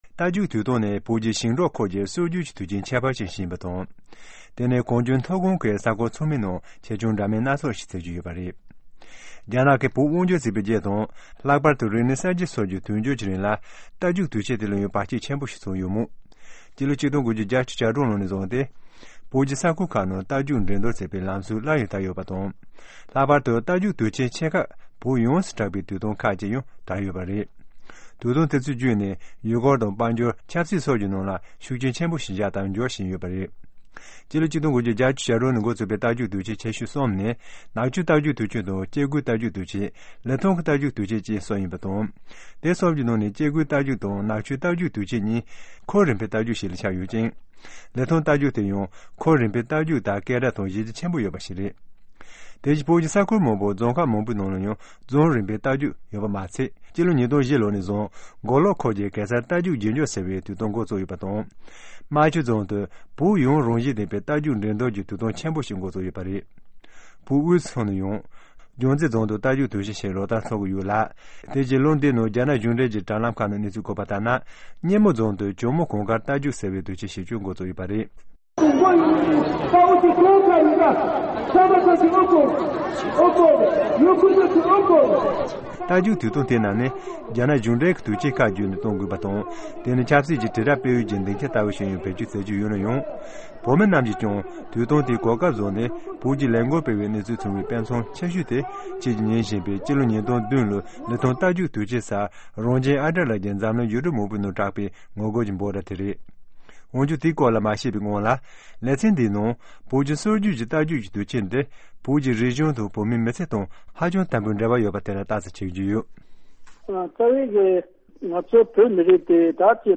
The memories of traditional horse race shared by four elder Tibetans in south India reveals how horse festivals played social, political, and cultural roles in old Tibet. The Tibetan government would collect tax during the event, local disputes were settled, younger generations learned traditional dances and other customs from the elders, who were mostly men because working class wives could not leave their daily chores at home.